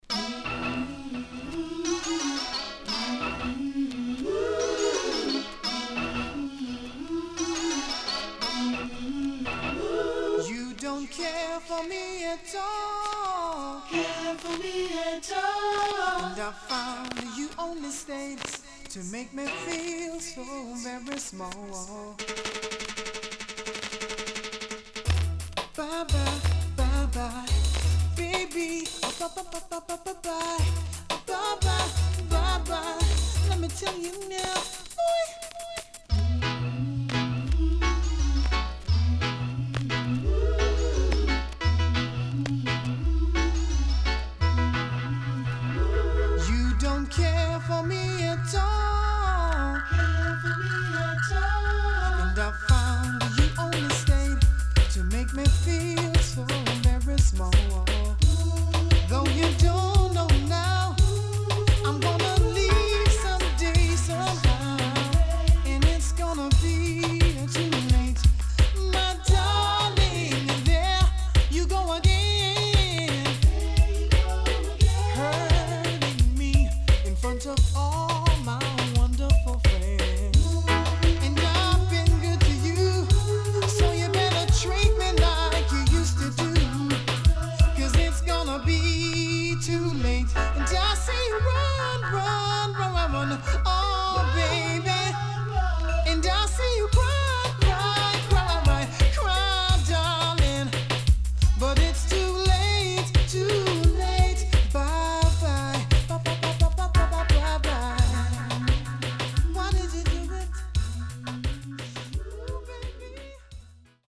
SIDE A:序盤プレス起因により少しノイズ入ります。